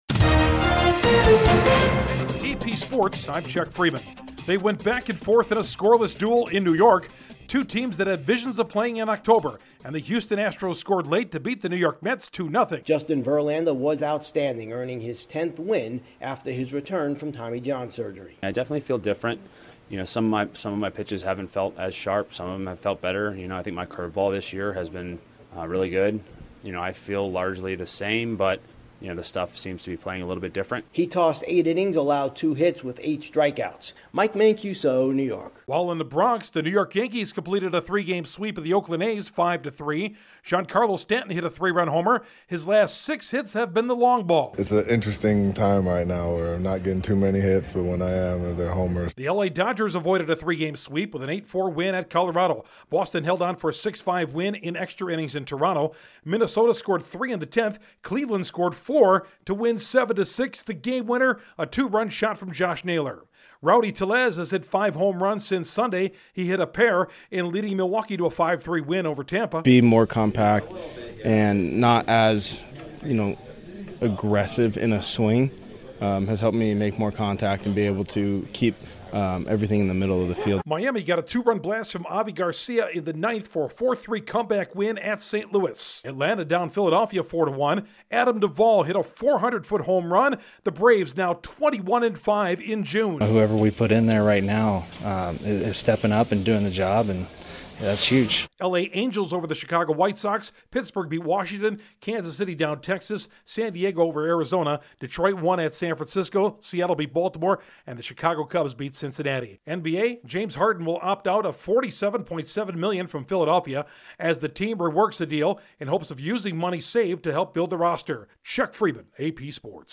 Justin Verlander lifts the Astros to a two-game season sweep of the Mets, Gianicarlo Stanton homers again to lead the Yankees, Rowdy Tellez muscles up twice in Milwaukee's win, the Braves keep winning, and James Harden opts out in Philly. Correspondent